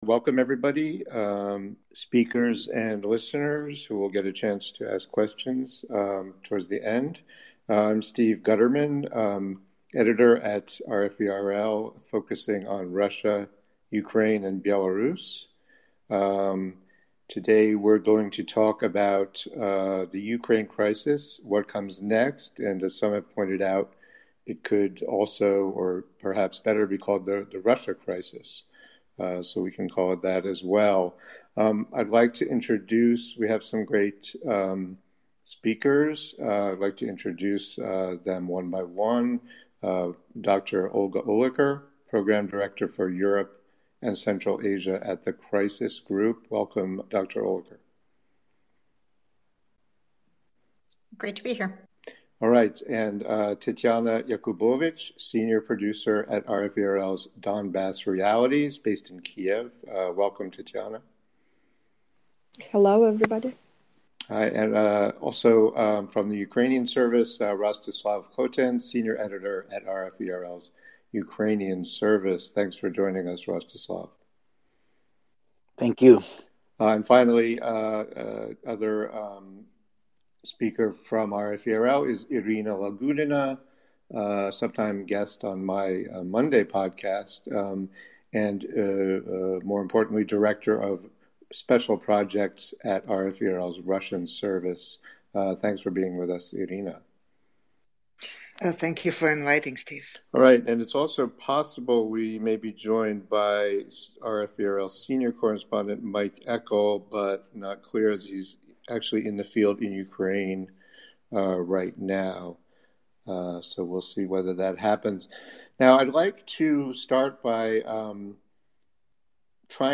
Will diplomacy prevail, or will Russia’s sweeping geopolitical demands and mounting military buildup near Ukraine lead to a new bloodshed? A Twitter Spaces conversation addressed the motives, actions, and potential outcomes at one of the tensest moments since the Cold War.